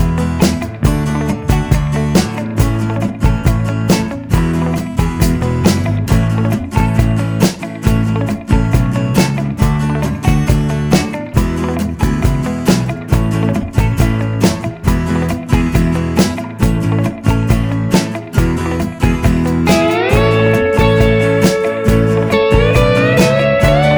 No Backing Vocals Rock 'n' Roll 2:48 Buy £1.50